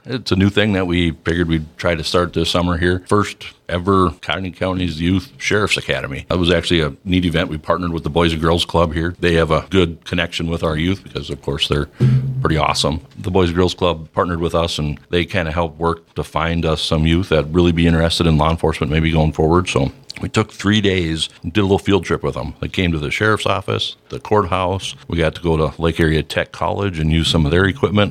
Sheriff Brad Howell described the academy.